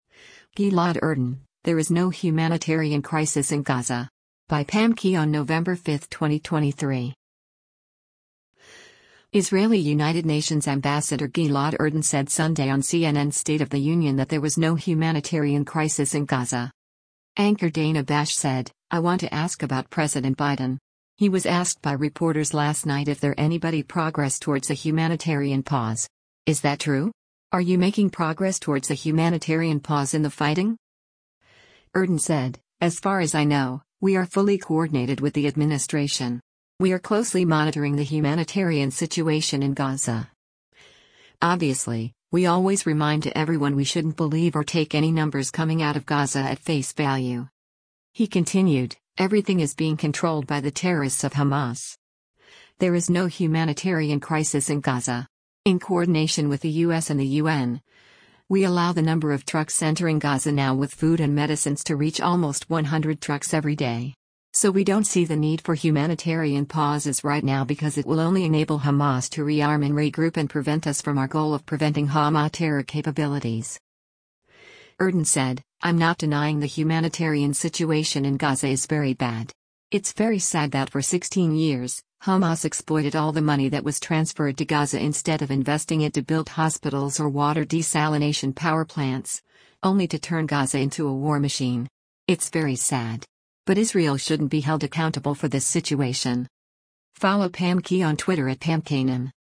Israeli United Nations ambassador Gilad Erdan said Sunday on CNN’s “State of the Union” that there was no humanitarian crisis in Gaza.